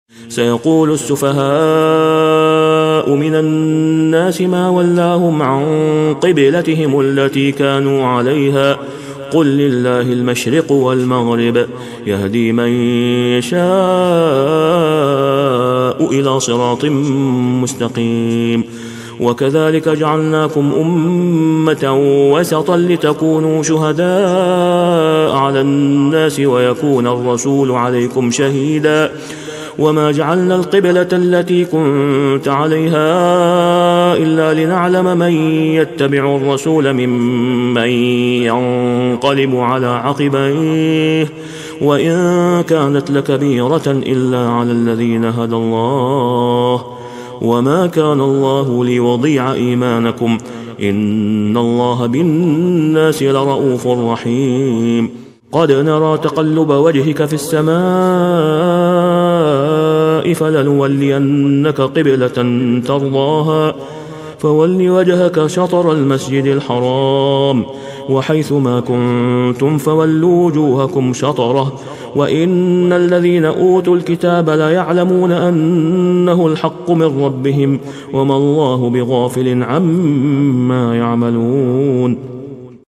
تسجيل إستديو نادر للشيخ إسامة خياط | سورة البقرة 142-144 > " تسجيلات إستديو للشيخ أسامة خياط" > المزيد - تلاوات الحرمين